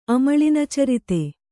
♪ amaḷinacarite